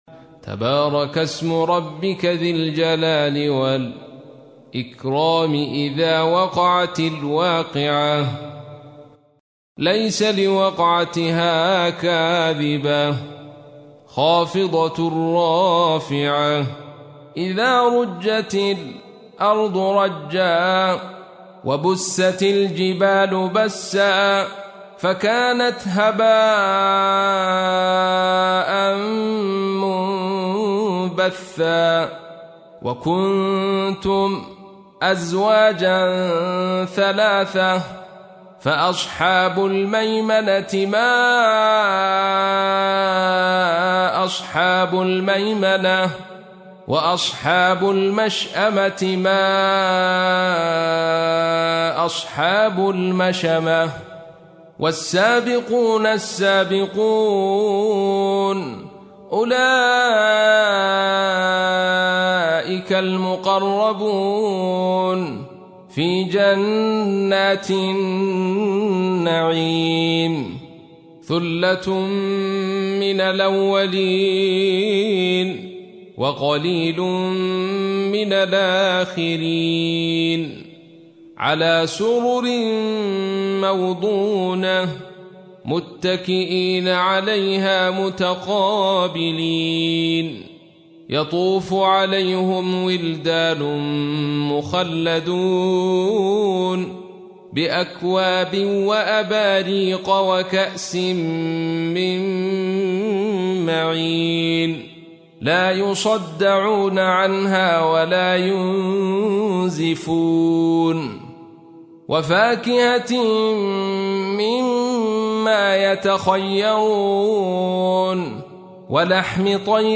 Audio Quran Tarteel Recitation Home Of Sheikh Abdul-Rasheed Soufi :: الشيخ عبد الرشيد صوفي | حفص عن عاصم Hafs for Assem
Surah Repeating تكرار السورة Download Surah حمّل السورة Reciting Murattalah Audio for 56. Surah Al-W�qi'ah سورة الواقعة N.B *Surah Includes Al-Basmalah Reciters Sequents تتابع التلاوات Reciters Repeats تكرار التلاوات